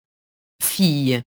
fille [fij]